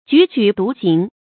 踽踽独行 jǔ jǔ dú xíng 成语解释 踽踽：孤独的样子。孤零零地独自走着。形容孤独无亲或独来独往。
成语繁体 踽踽獨行 成语简拼 jjdx 成语注音 ㄐㄨˇ ㄐㄨˇ ㄉㄨˊ ㄒㄧㄥˊ 感情色彩 中性成语 成语用法 偏正式；作谓语、宾语、状语；指人独自走路 成语结构 偏正式成语 产生年代 古代成语 成语正音 踽，不能读作“yǔ”。